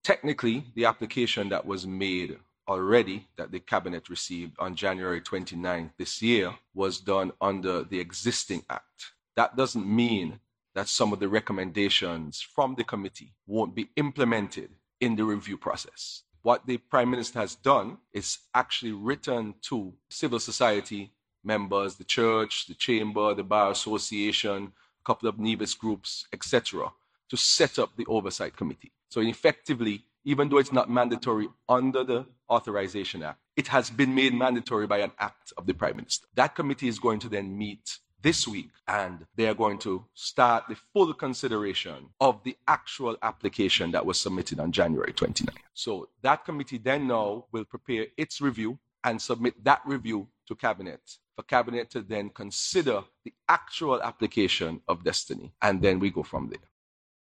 This is in connection with the proposed Destiny Project on the Southern Coast of Nevis. During the Prime Minister’s press conference with his Cabinet Ministers on Mar. 30th, AG Wilkin explained further what the process entails with the Oversight Committee, etc.